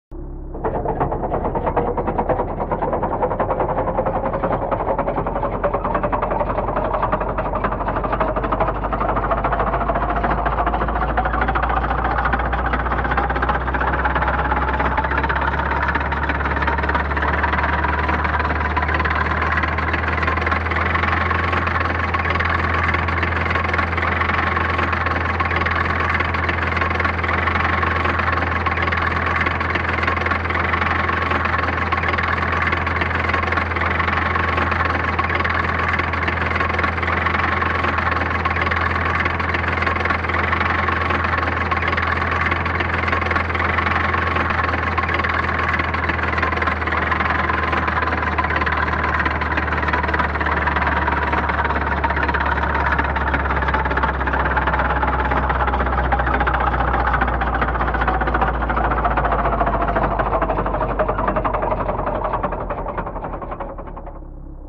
BSG FX - Landram - Idle, drive fast, stop
BSG_FX_-_Landram_-_Idle2C_drive_fast2C_stop.wav